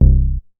MoogDown 005.WAV